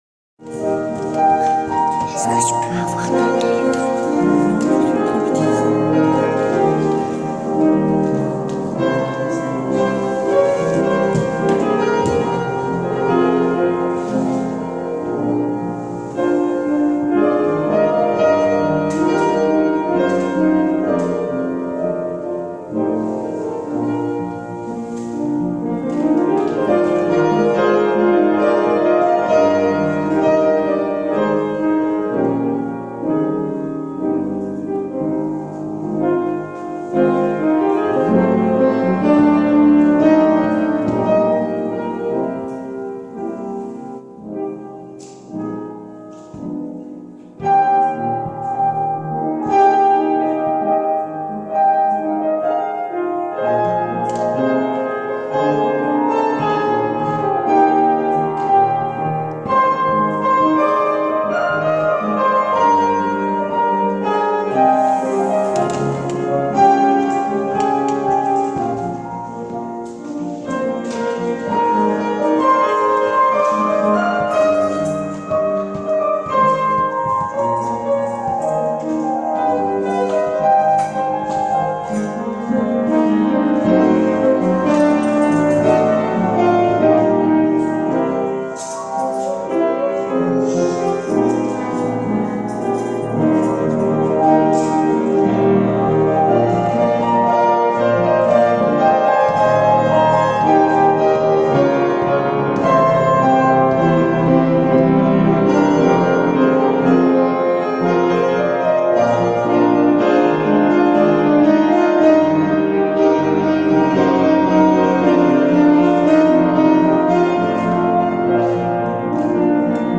LOUANGES – INTERLUDES